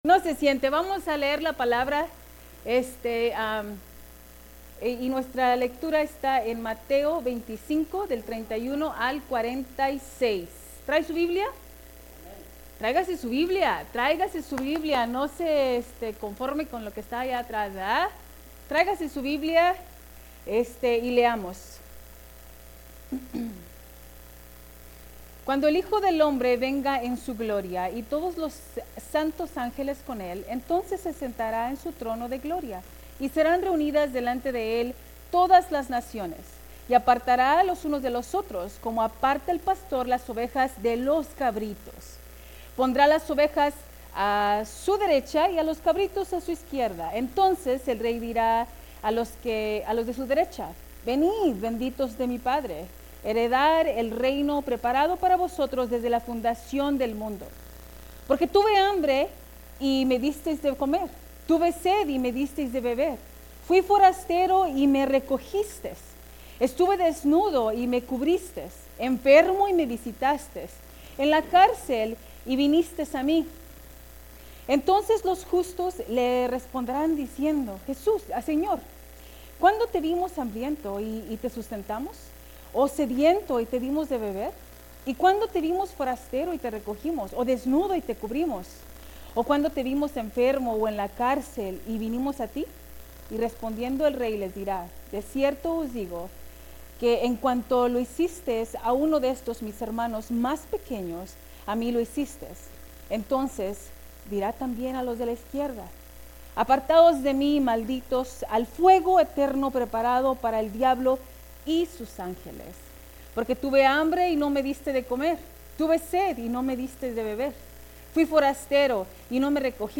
Sermons | Mercy Springs Church of the Nazarene
Si se lo perdieron o simplemente quieren revivir la inspiración, ¡pueden encontrar el servicio completo aquí!